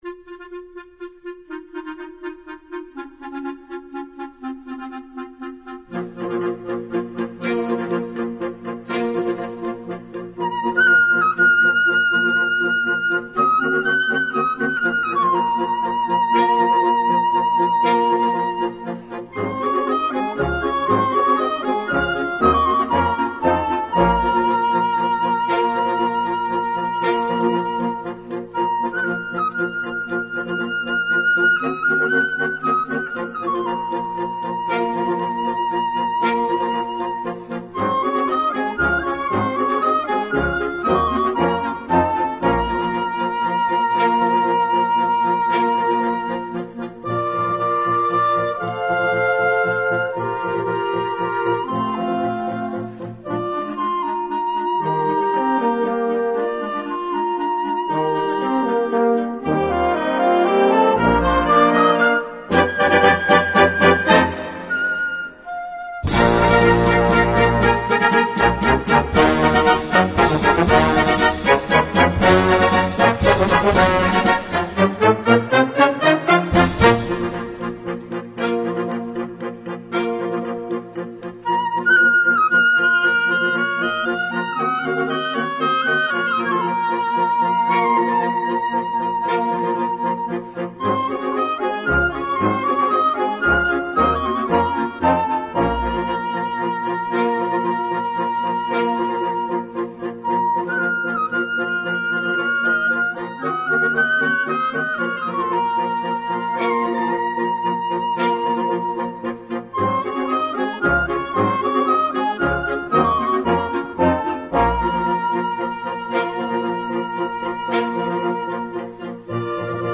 Voicing: Flute Solo w/ Band